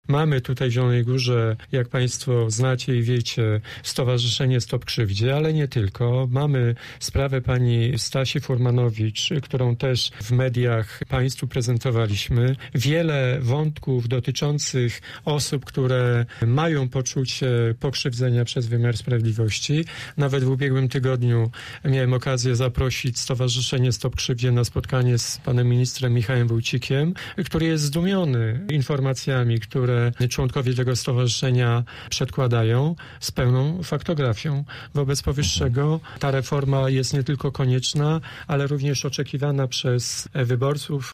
Reforma wymiaru sprawiedliwości tematem rozmowy z posłem PiS Jackiem Kurzępą na naszej antenie.